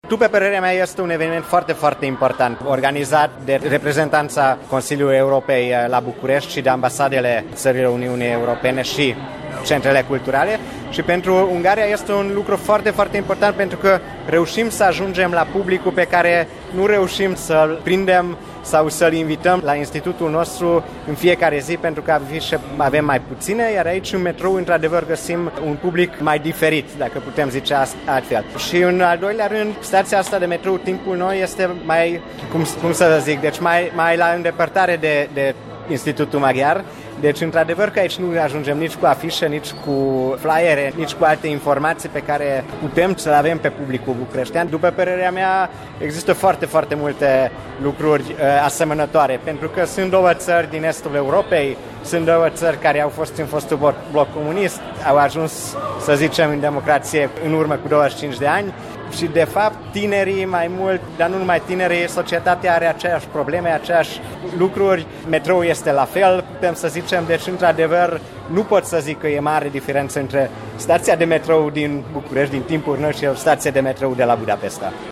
precum şi două declaraţii despre însemnătatea zilei de 9 Mai